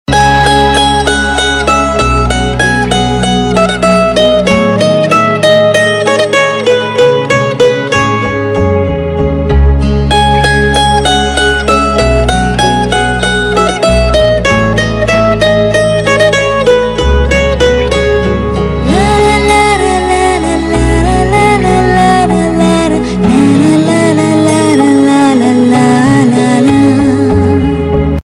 TV Serial Tone